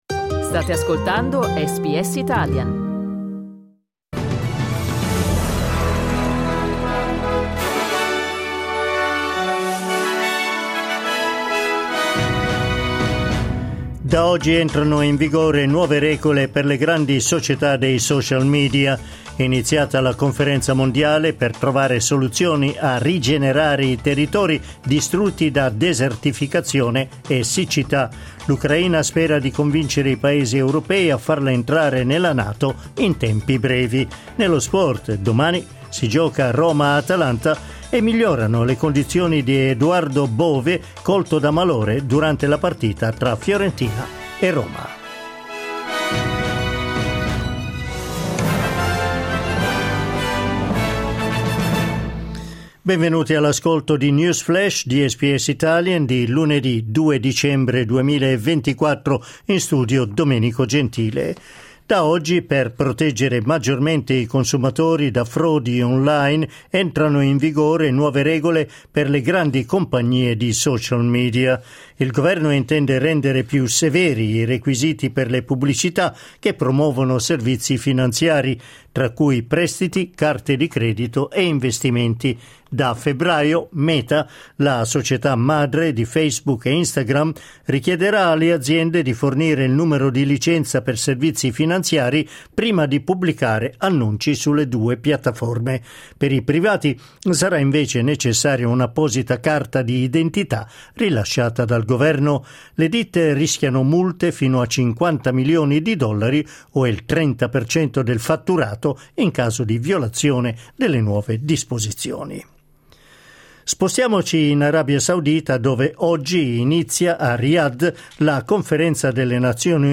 News flash lunedì 2 dicembre 2024